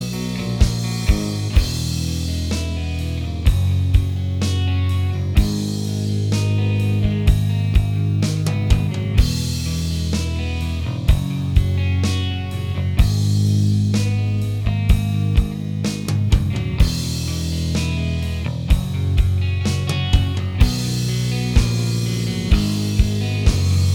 Minus Guitars Rock 4:21 Buy £1.50